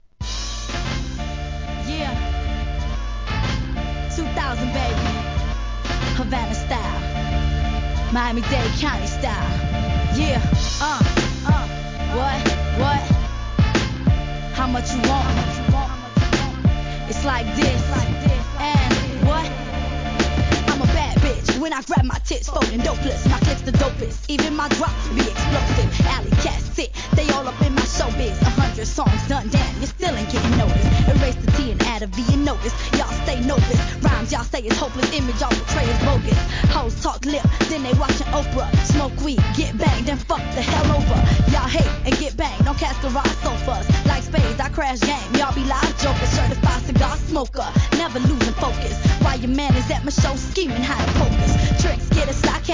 HIP HOP/R&B
フィメールRAP!!